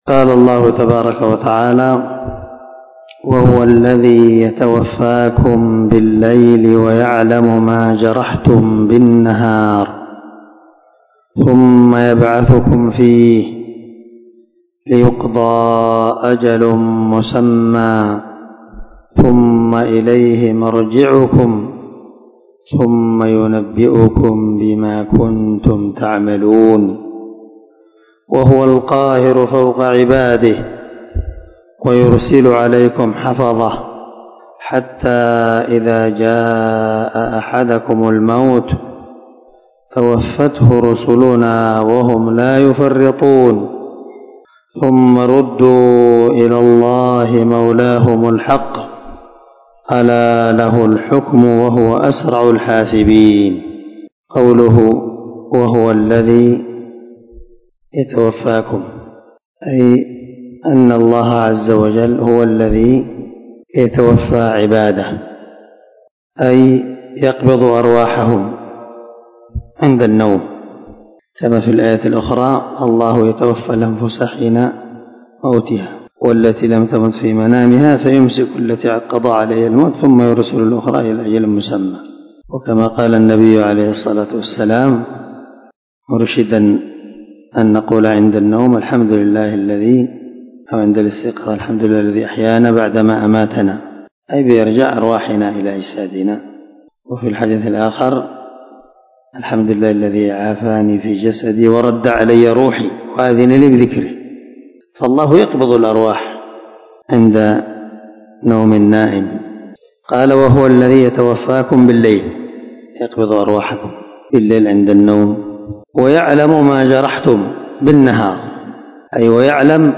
410الدرس 18 تفسير آية ( 60 – 62 ) من سورة الأنعام من تفسير القران الكريم مع قراءة لتفسير السعدي